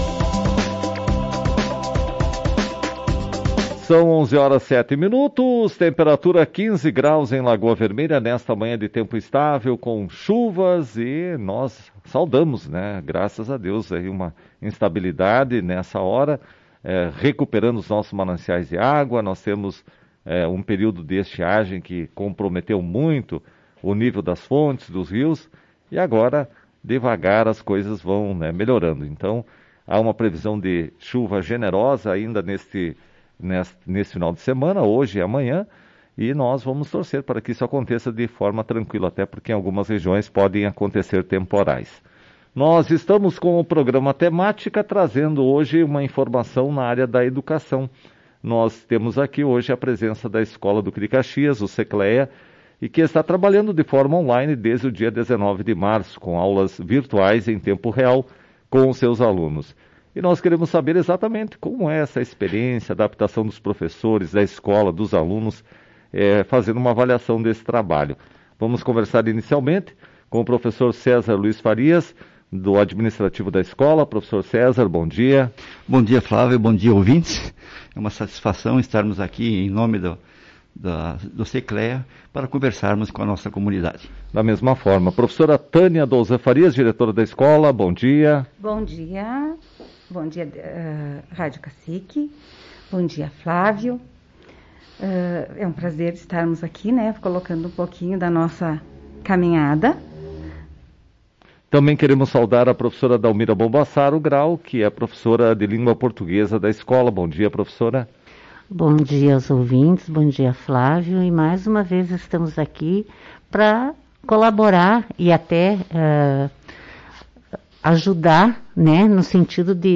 Em entrevista à Tua Rádio, os profissionais que trabalham na intuição falaram sobre o processo de mudança.